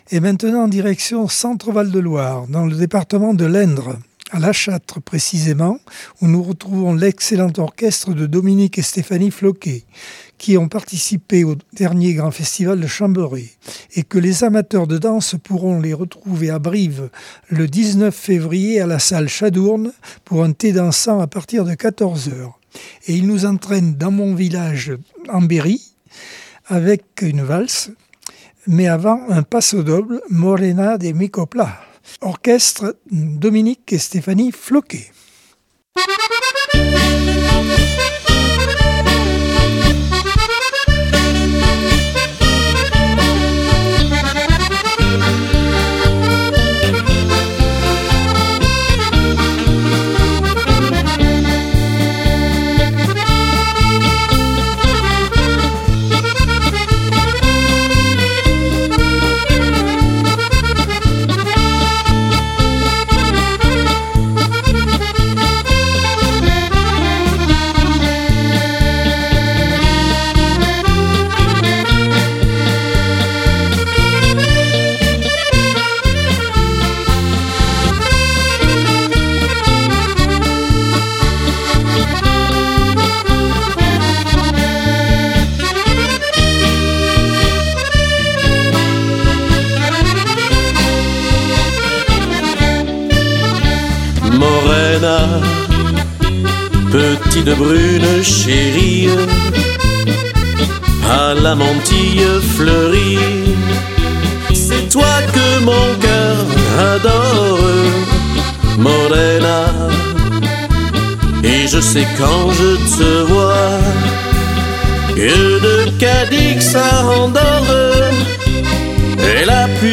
Accordeon 2026 sem 05 bloc 2.